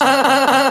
Play, download and share stomedy laugh original sound button!!!!
stomedy-laugh.mp3